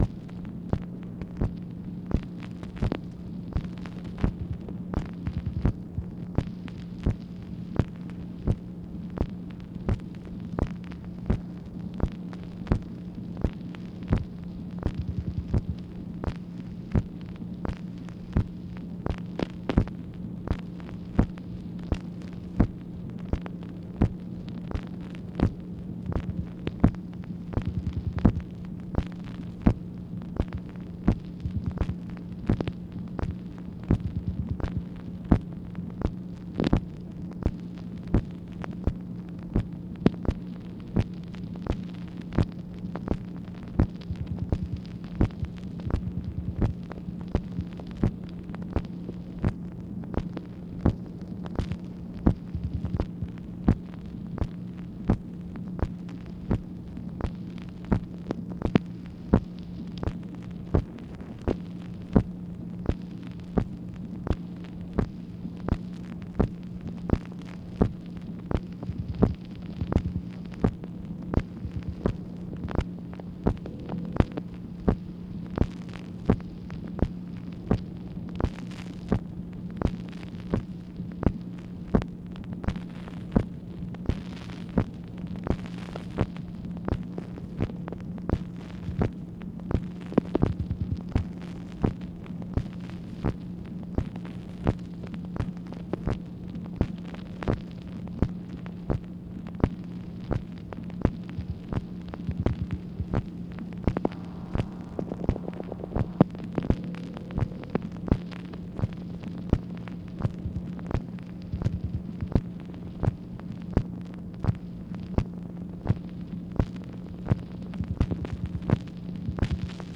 MACHINE NOISE, May 1, 1964
Secret White House Tapes | Lyndon B. Johnson Presidency